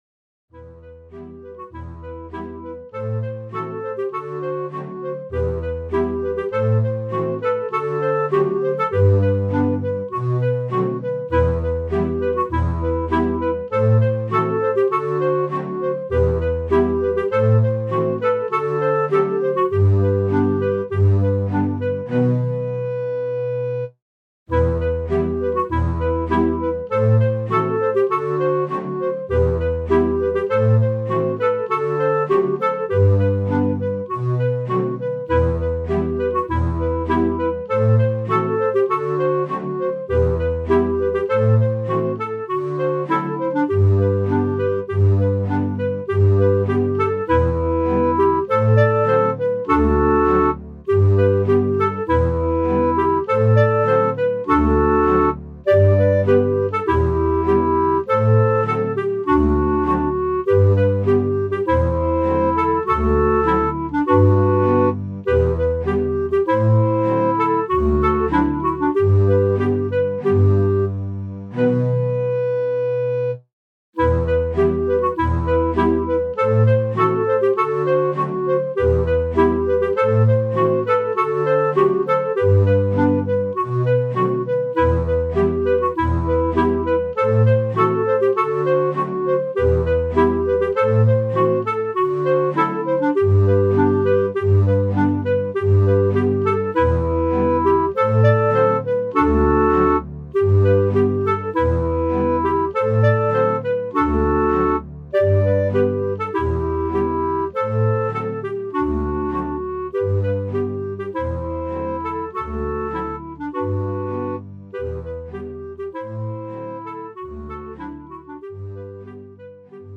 Mehrstimmige Melodien mit wundersamen Klängen